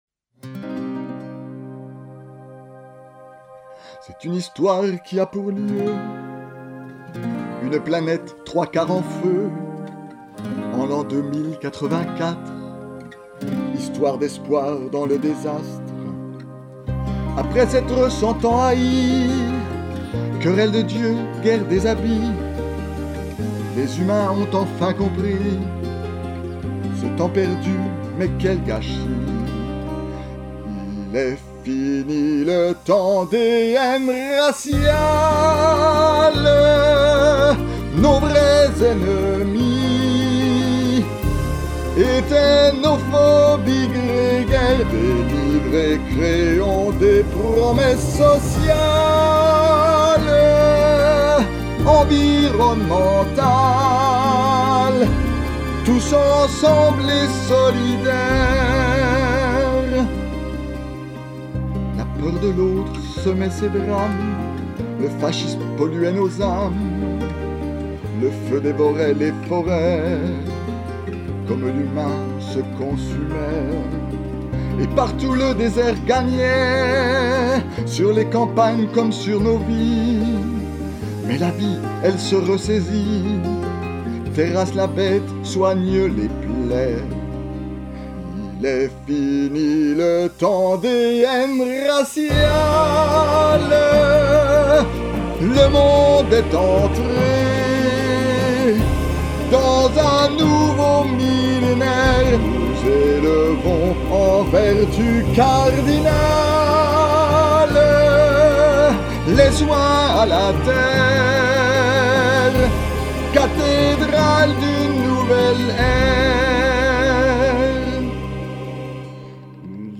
Il-est-fini-le-temps-une-voix.mp3